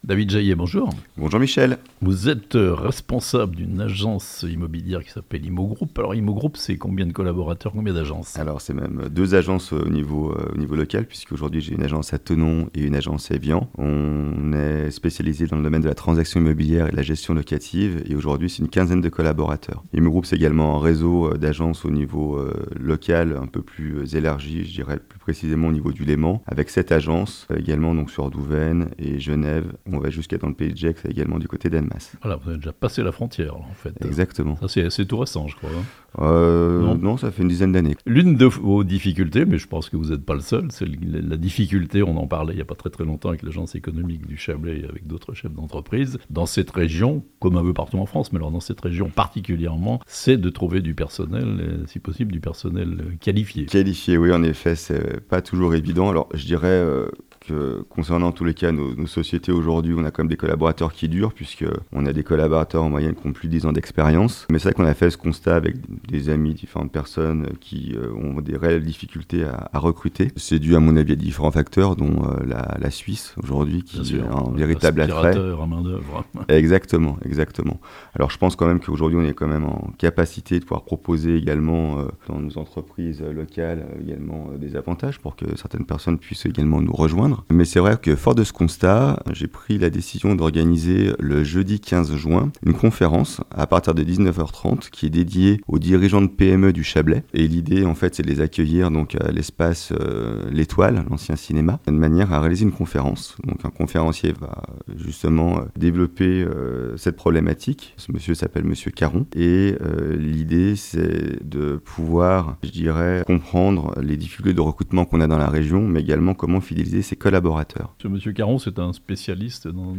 Thonon : une conférence destinée aux chefs d'entreprise qui ont des difficultés à recruter (interview)